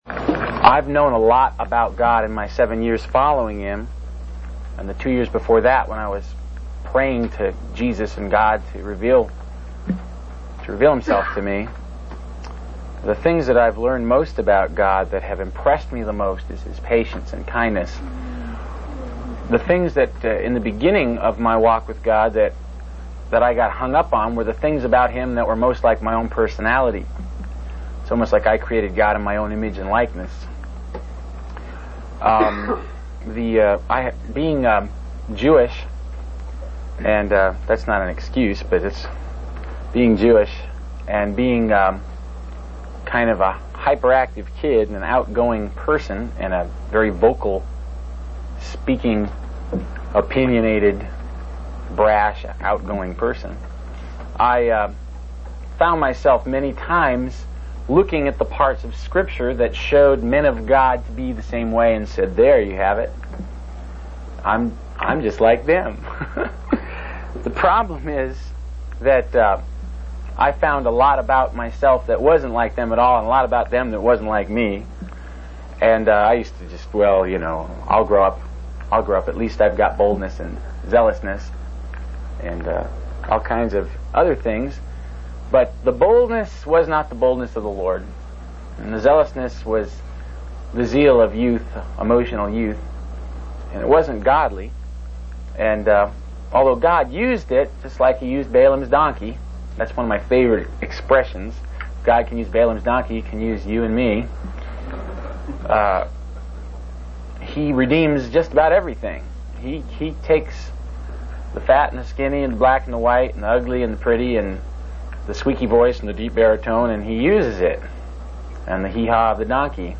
This sermon reflects on the speaker's journey of understanding God's character, particularly His patience and kindness, and the realization of personal shortcomings in comparison to biblical figures. It emphasizes the importance of humility, teachability, and openness to correction in one's Christian walk.